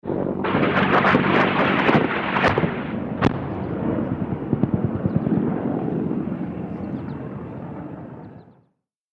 HELICOPTER BELL AH-1 SEA COBRA: EXT: Hovering distant. Rocket fires & strikes.